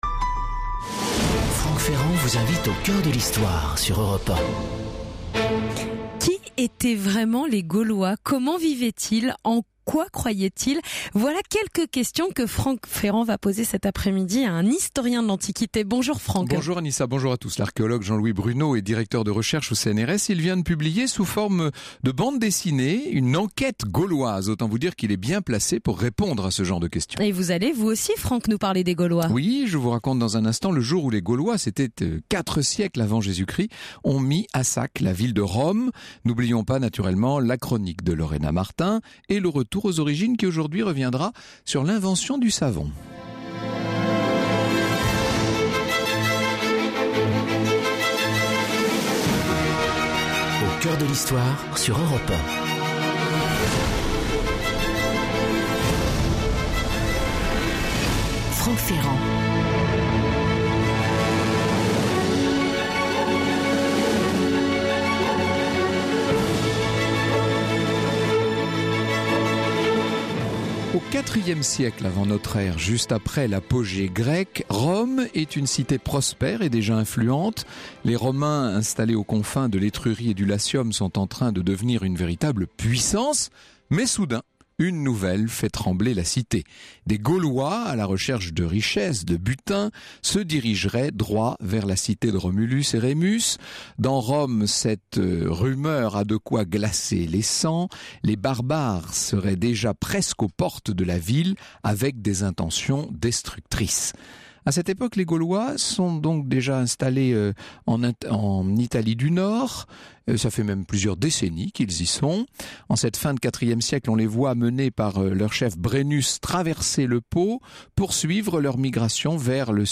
A titre de préambule, Franck Ferrand nous raconte le jour où les Gaulois – c’était quatre siècles avant Jésus-Christ – ont mis à sac la ville de Rome.